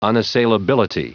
Prononciation du mot : unassailability